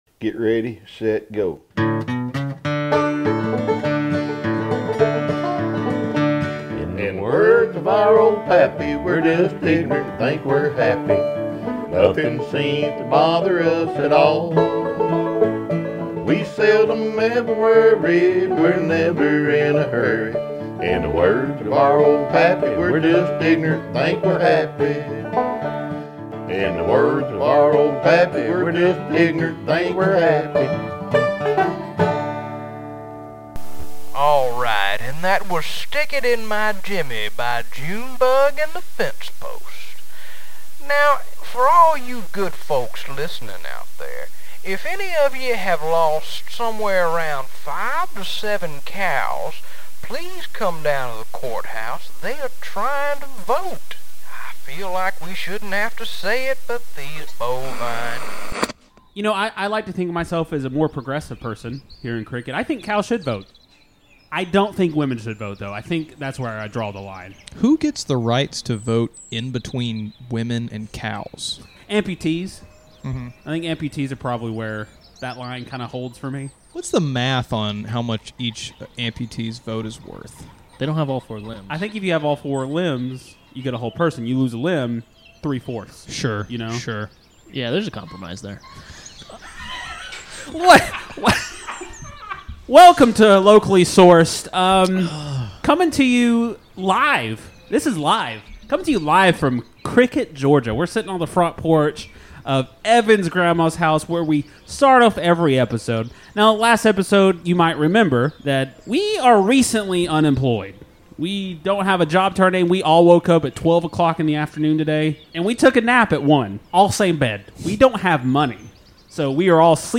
Be a guest on this podcast Language: en Genres: Comedy , Improv , Stand-Up Contact email: Get it Feed URL: Get it iTunes ID: Get it Get all podcast data Listen Now...